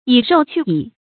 以肉去蟻 注音： ㄧˇ ㄖㄡˋ ㄑㄩˋ ㄧˇ 讀音讀法： 意思解釋： 用肉驅趕螞蟻，螞蟻越多。